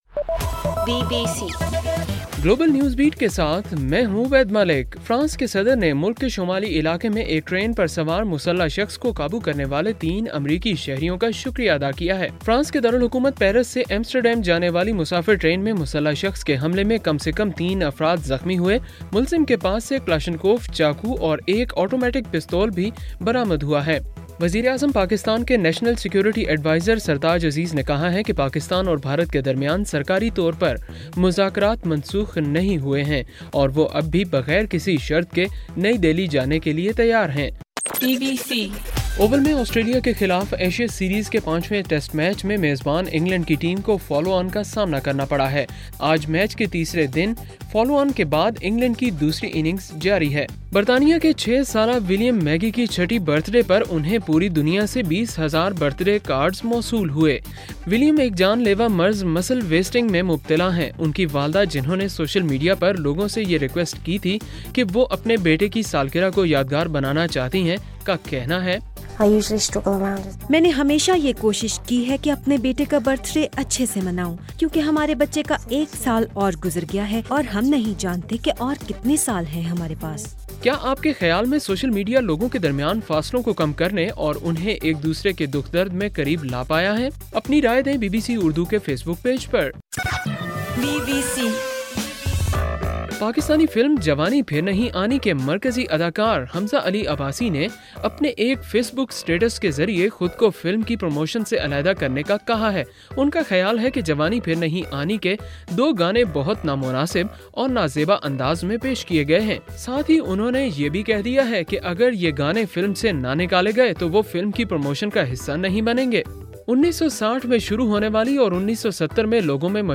اگست 22: رات 8 بجے کا گلوبل نیوز بیٹ بُلیٹن